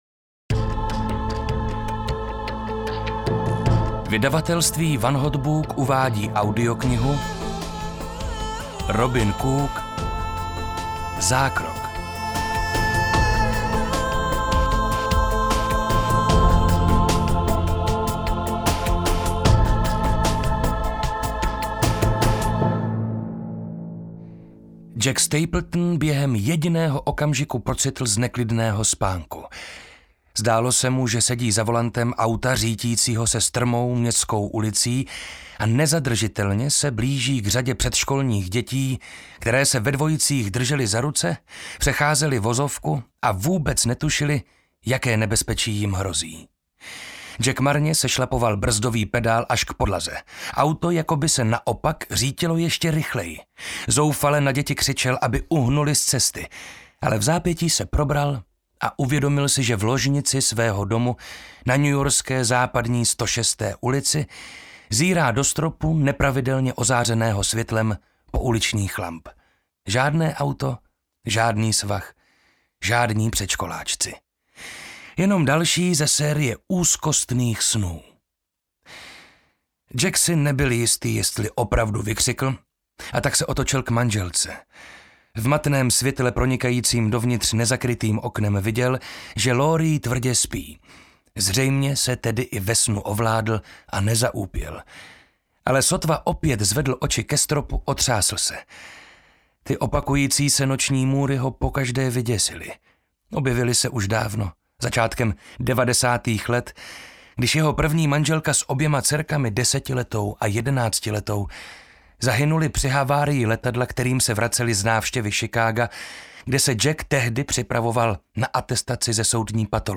Zákrok audiokniha
Ukázka z knihy
• InterpretMarek Holý